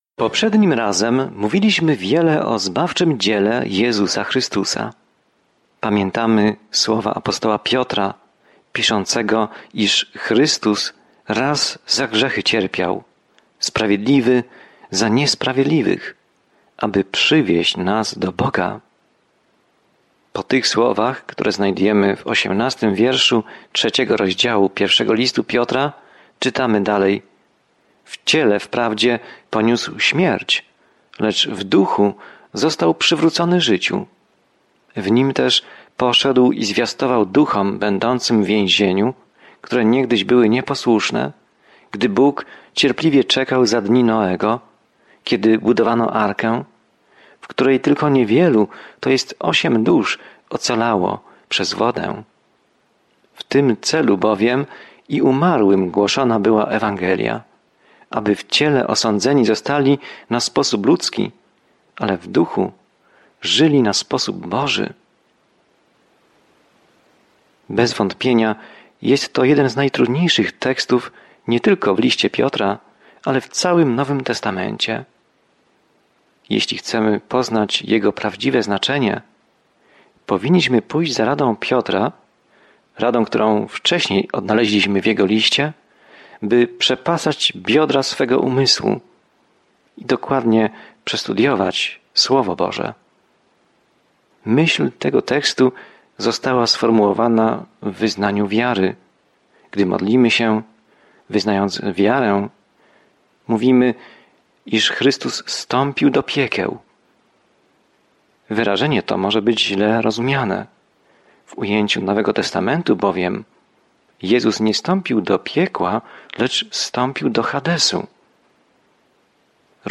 Pismo Święte 1 Piotra 3:18-22 Dzień 11 Rozpocznij ten plan Dzień 13 O tym planie Jeśli cierpisz dla Jezusa, ten pierwszy list Piotra zachęca cię, abyś podążał śladami Jezusa, który pierwszy cierpiał za nas. Codziennie podróżuj przez I List Piotra, słuchając studium audio i czytając wybrane wersety ze słowa Bożego.